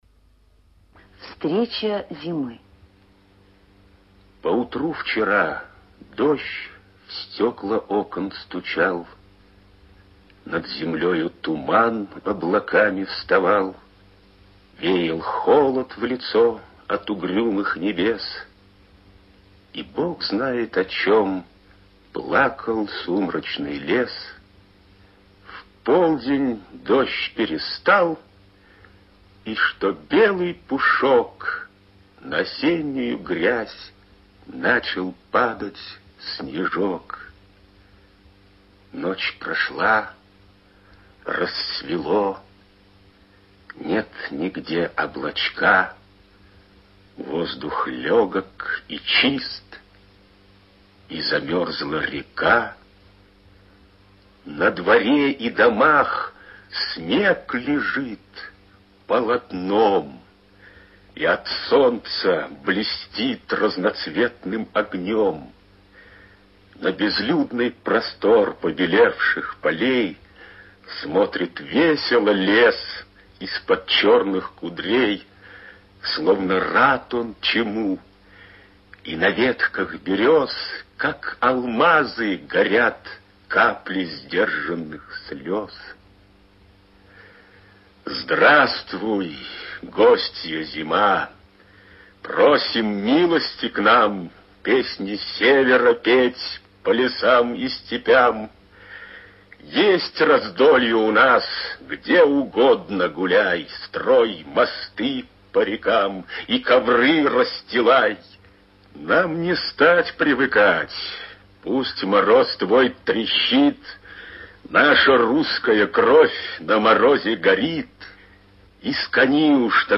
Прослушивание аудиозаписи стихотворения с сайта «Старое радио»